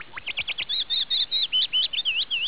Virtual tour of Petaluma's Lafferty Ranch in spring
warbler.aiff